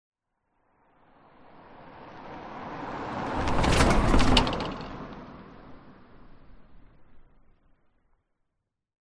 Звуки лежачего полицейского
На этой странице вы можете скачать и слушать звуки лежачего полицейского – реалистичные аудиофайлы, записанные с разных поверхностей и скоростей.
Звук машины переезжающей бордюр